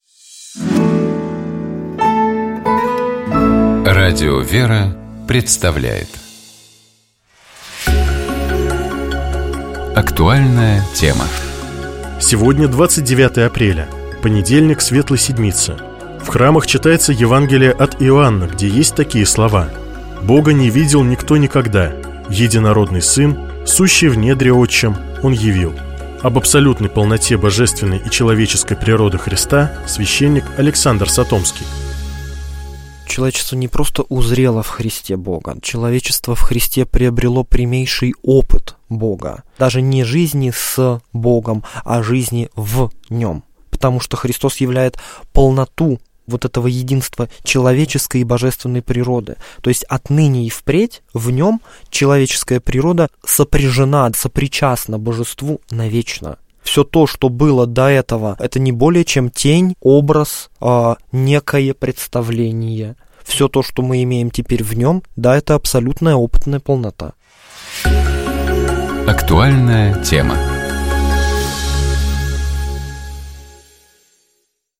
В храмах читается Евангелие от Иоанна, где есть такие слова: «Бога не видел никто никогда; Единородный Сын, сущий в недре Отчем, Он явил». Об абсолютной полноте божественной и человеческой природы Христа, — священник